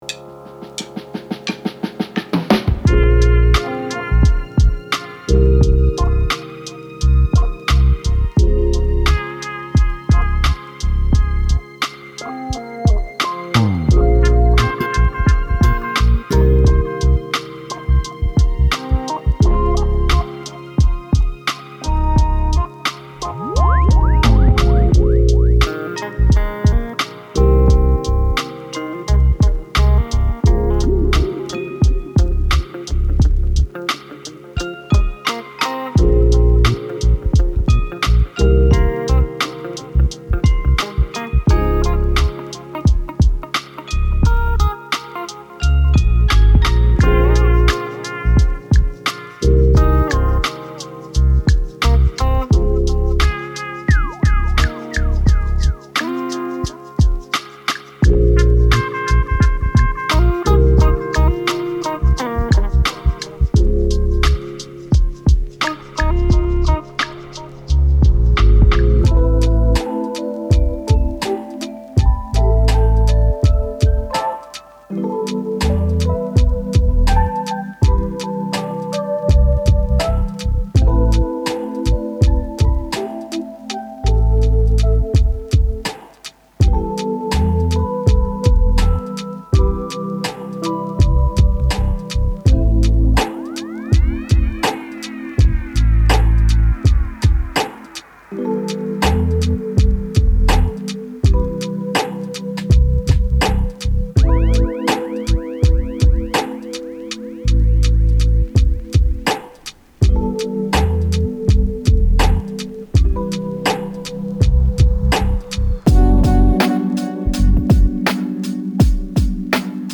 Genre:Neo Soul
デモサウンドはコチラ↓
25 Drum break loops
68 - 90 Bpm